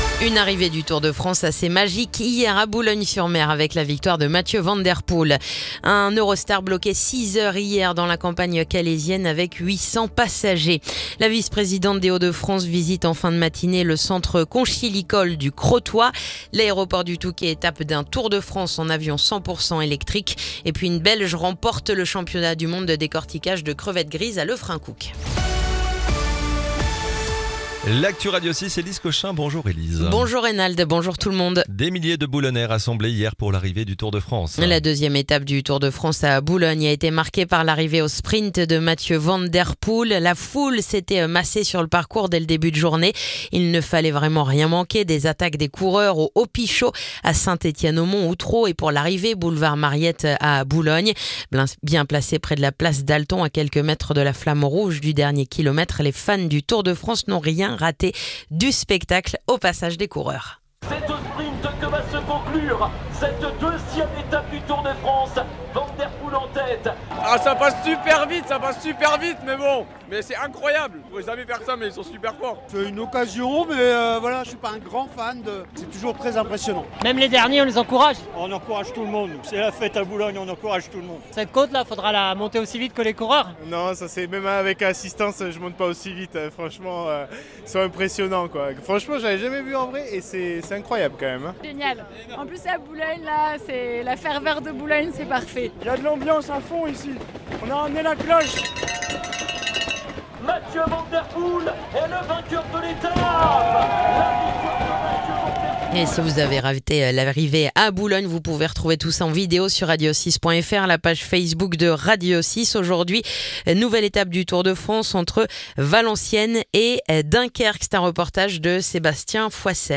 Le journal du lundi 7 juillet